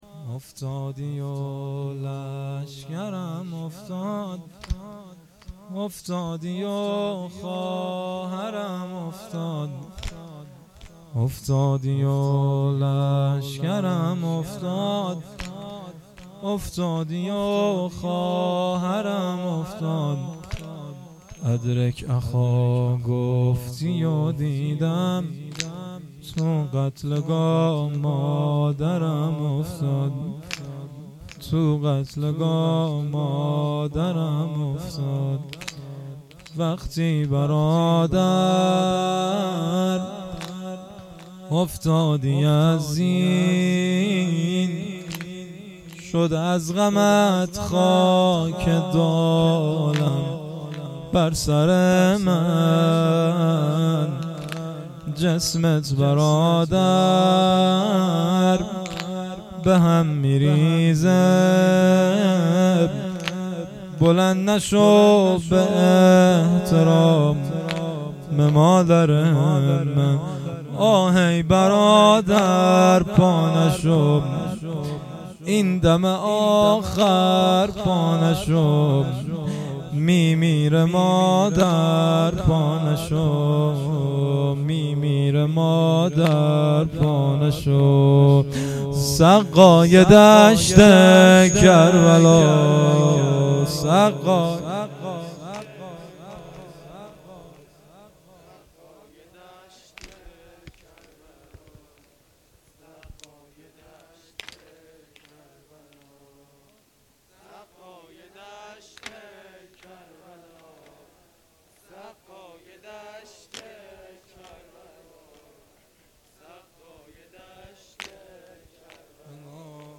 مراسم عزاداری دهه اول محرم الحرام 1399 - مسجد صاحب الزمان (عج) هرمزآباد